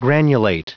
Prononciation du mot granulate en anglais (fichier audio)
Prononciation du mot : granulate